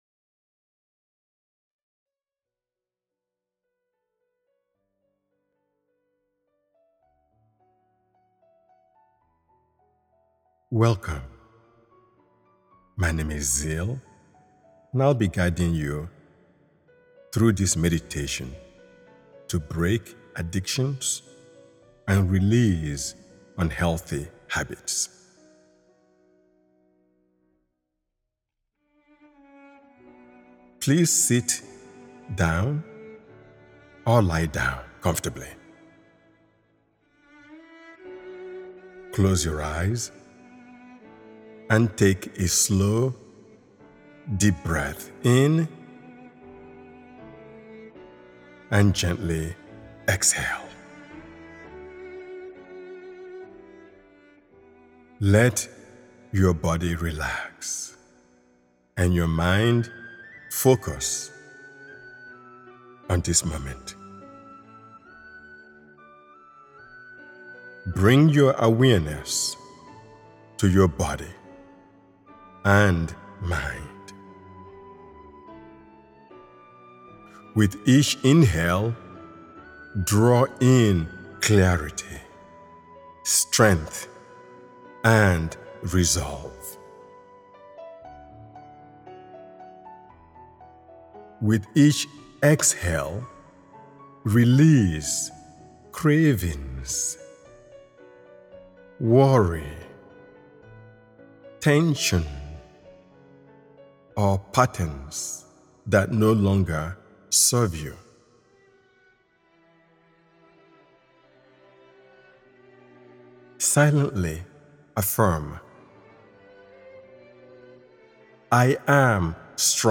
Break Addictions & Release Unhealthy Habits: A Guided Meditation for Inner Freedom, Healing & Personal Transformation
Over 20 affirmations are used throughout the meditation to reinforce clarity, self-discipline, and emotional resilience.